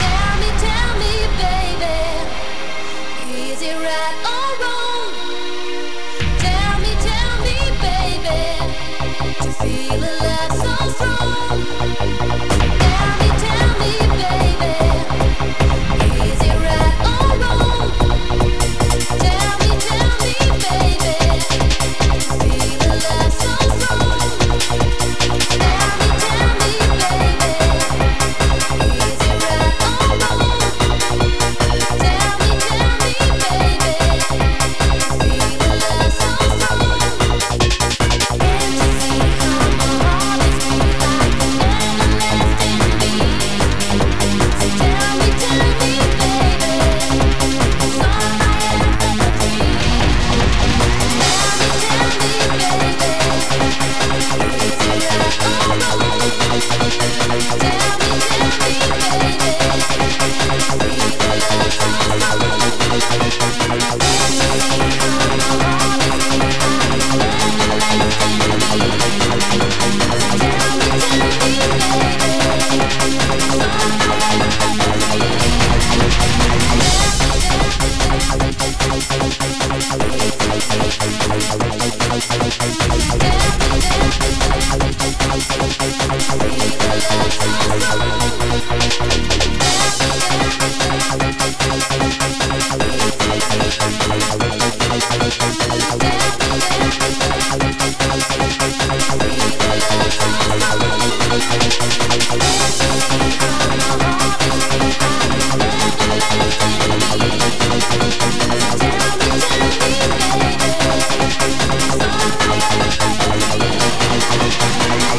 ( .mp3 ) < prev next > Protracker Module | 1996-11-28 | 805KB | 2 channels | 44,100 sample rate | 1 minute, 55 seconds Title tellme...